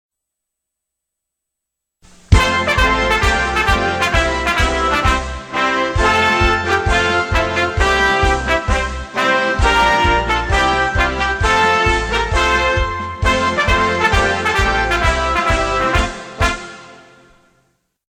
典禮開幕樂-奏樂.mp3
典禮開幕樂-奏樂.mp3.ogg